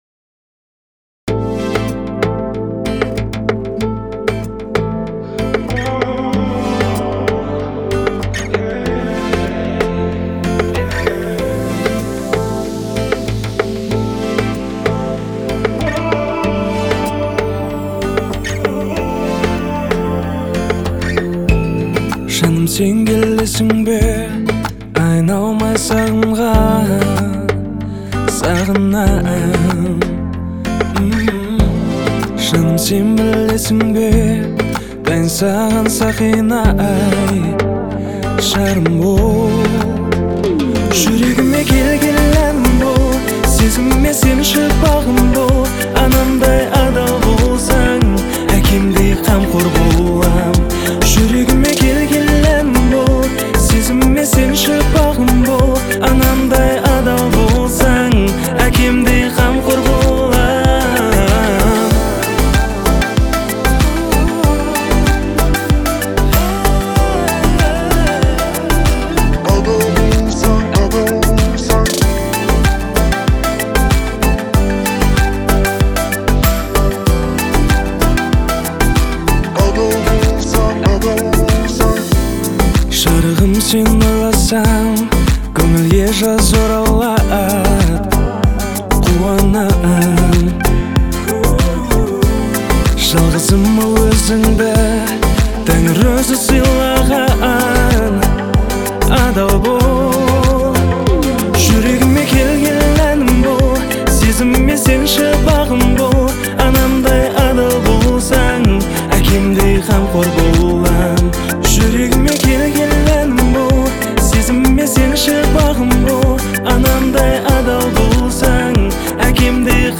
который сочетает в себе элементы поп и народной музыки.